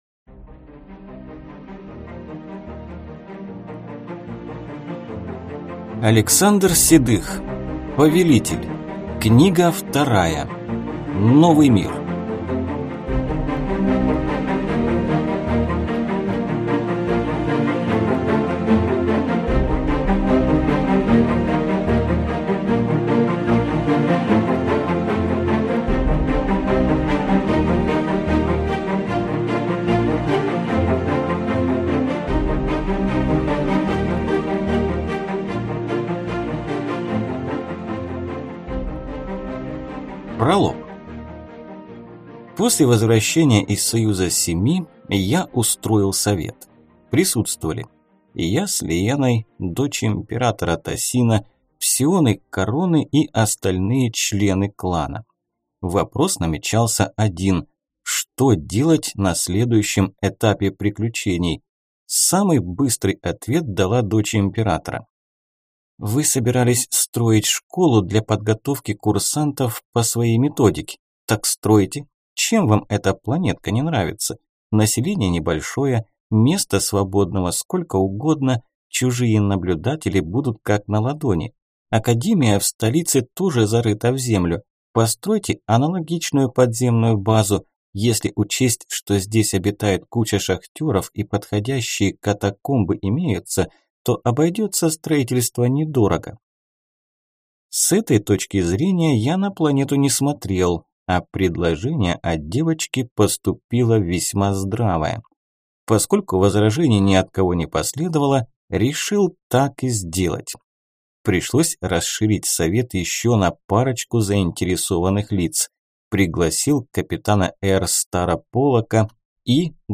Аудиокнига Повелитель. Книга 2. Новый мир | Библиотека аудиокниг